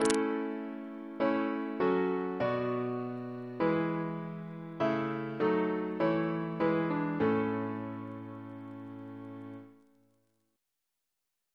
Single chant in A Composer: Vincent Novello (1781-1861) Reference psalters: ACB: 192; PP/SNCB: 224; RSCM: 200